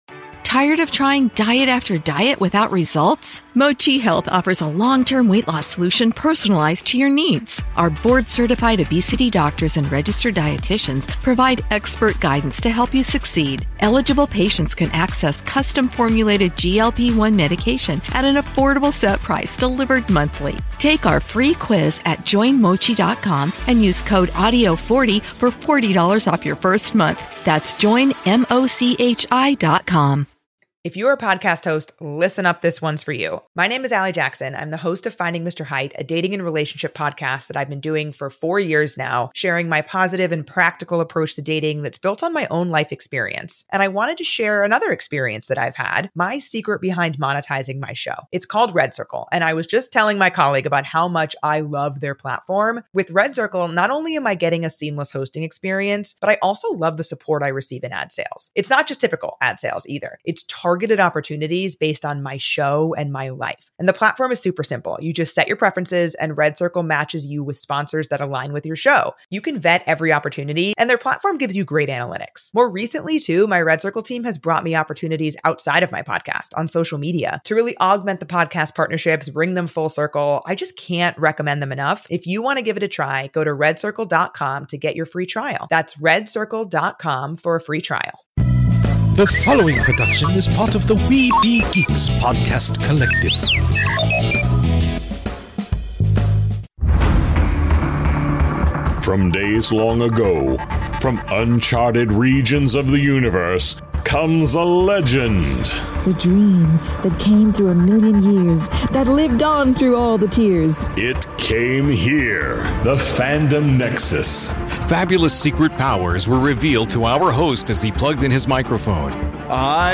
On this episode of The Fandom Nexus, we’re diving into some of the most exciting announcements from Summer Game Fest 2025—including a few surprises that have classic gaming fans buzzing. Then we travel back to the 1980s with a special panel recorded live at Planet Comicon Kansas City, featuring the legendary Melendy Britt, the voice of Adora and She-Ra herself! From her time defending Etheria to stories about the golden age of animation, this conversation is a must-listen for fans of She-Ra: Princess of Power and classic Saturday morning cartoons.